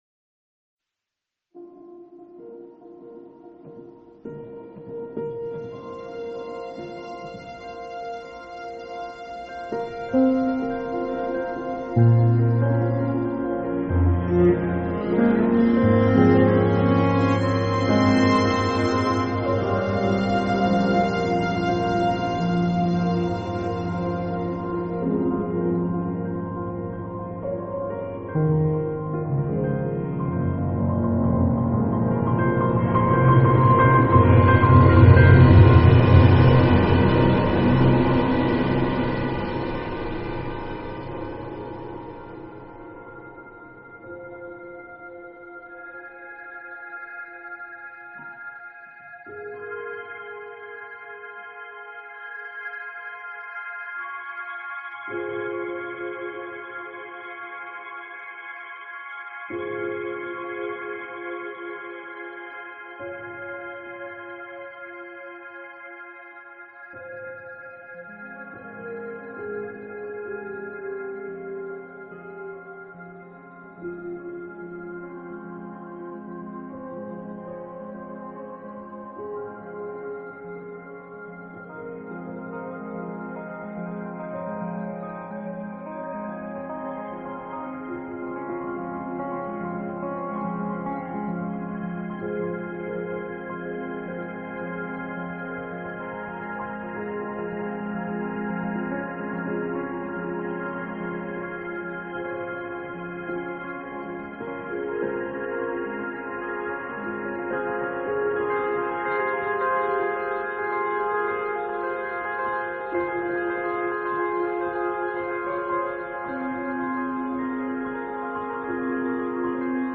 Soundtrack, Horror